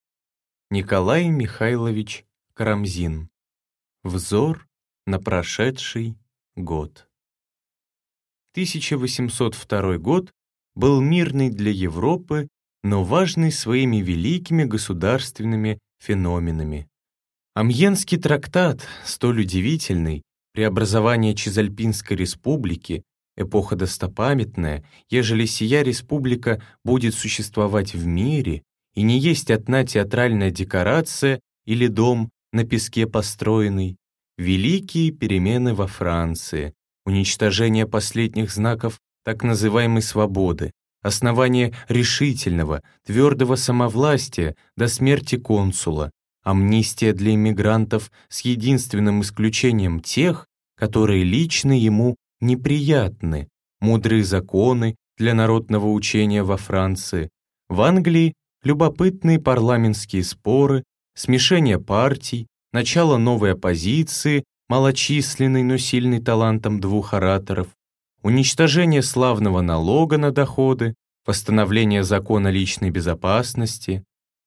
Аудиокнига Взор на прошедший год | Библиотека аудиокниг